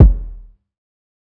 TC2 Kicks12.wav